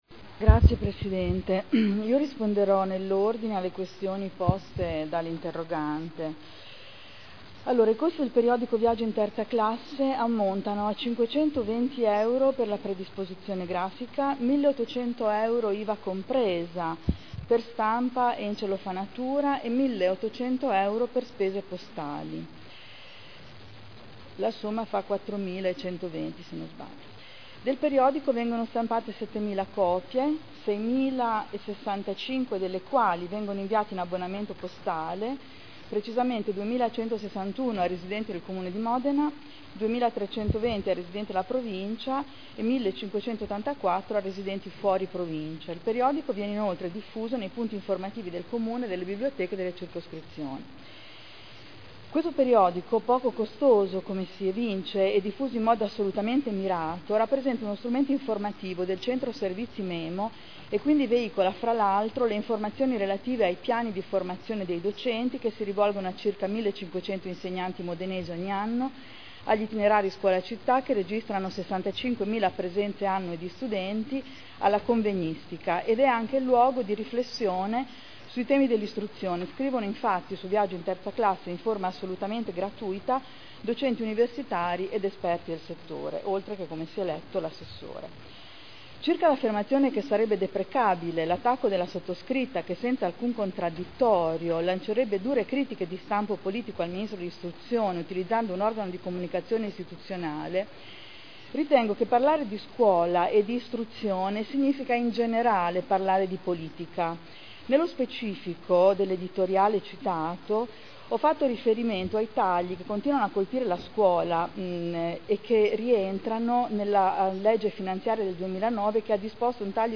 Seduta del 30/11/2009. Viaggio in terza classe.